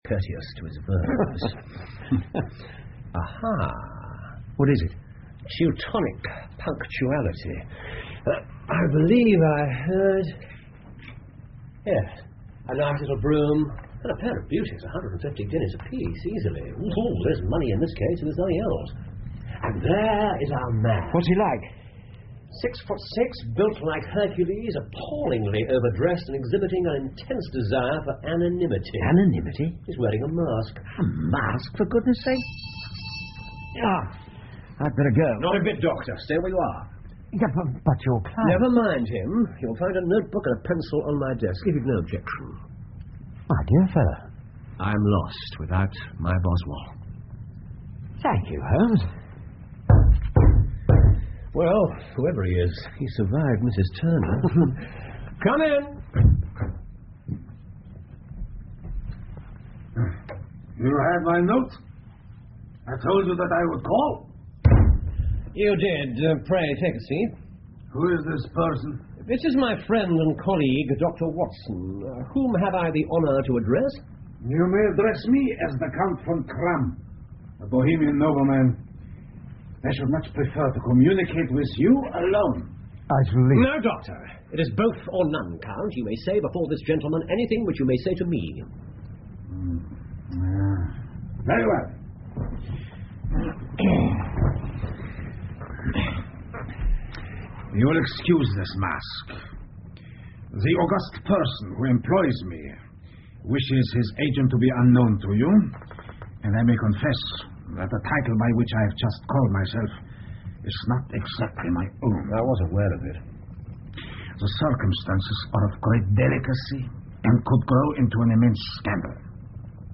福尔摩斯广播剧 A Scandal In Bohemia 3 听力文件下载—在线英语听力室